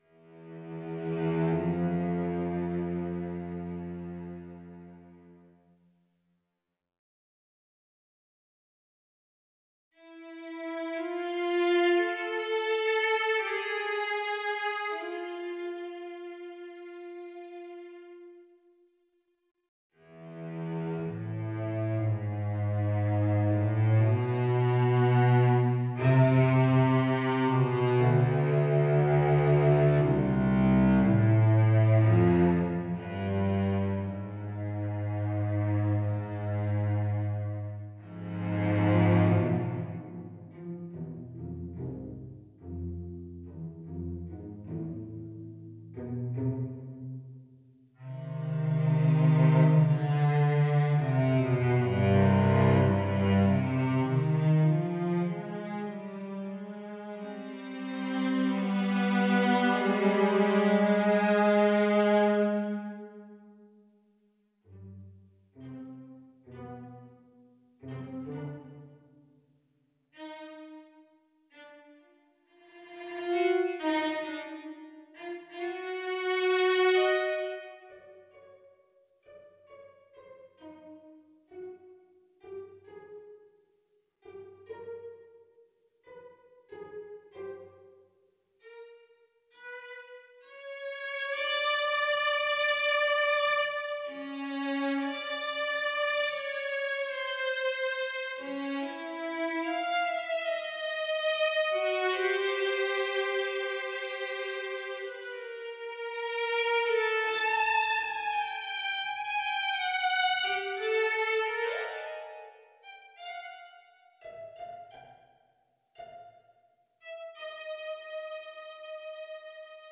For Cello Solo